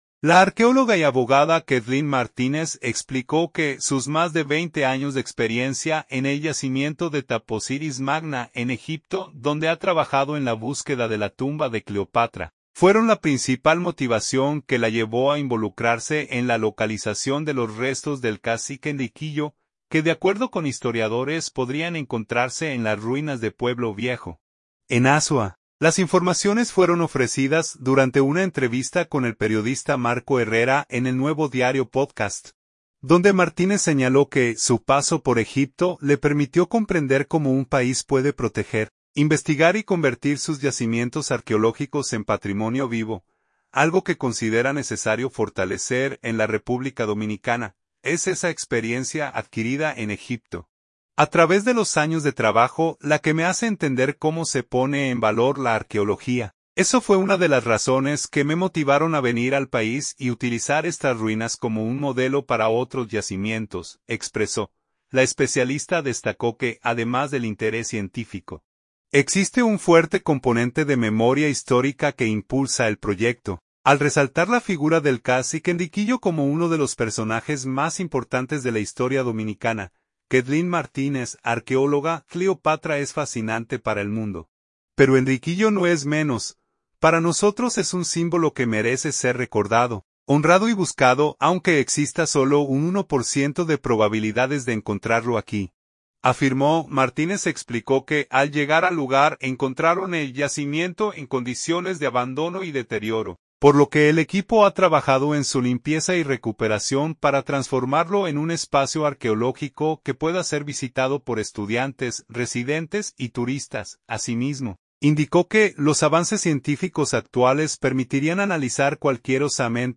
Kathleen Martínez, arqueóloga